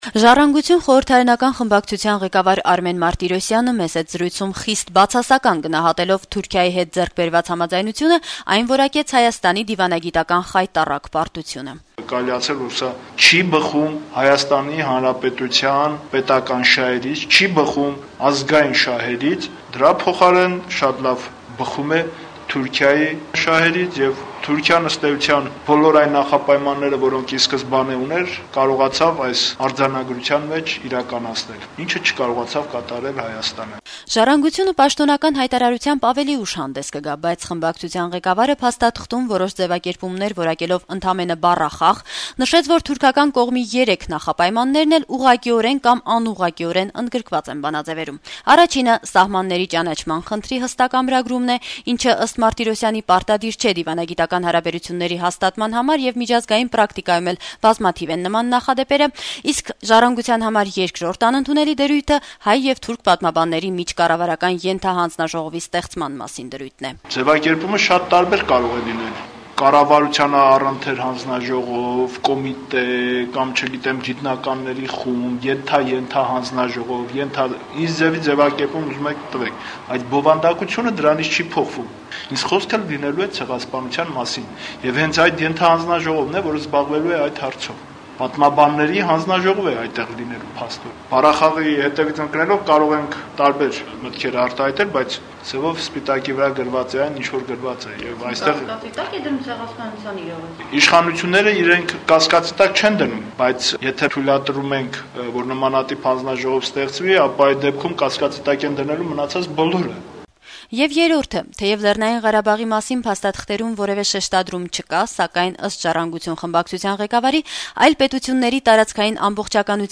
«Ժառանգություն» ընդդիմադիր կուսակցության խորհրդարանական խմբակցության ղեկավար Արմեն Մարտիրոսյանը «Ազատություն» ռադիոկայանի հետ զրույցում Հայաստանի դիվանագիտական «խայտառակ պարտություն» որակեց Հայաստանի եւ Թուրքիայի միջեւ նախաստորագրված արձանագրությունները: